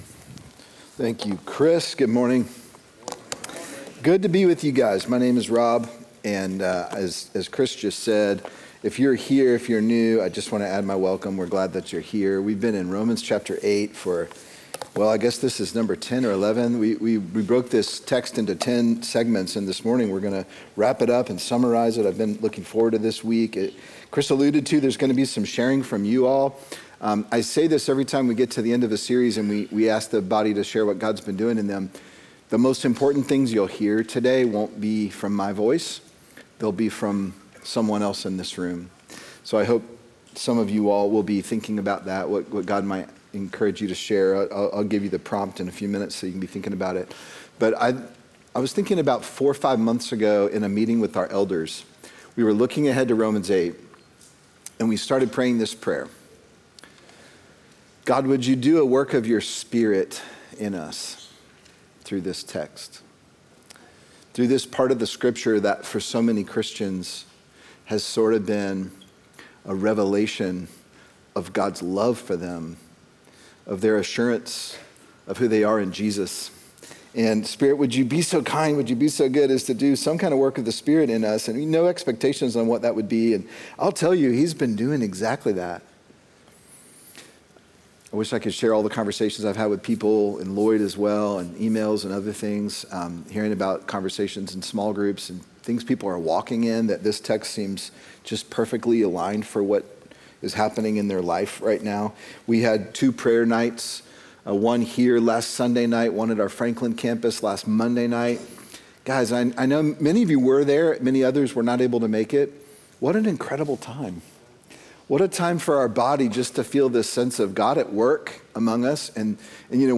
Sermon Unshakeable: Romans 8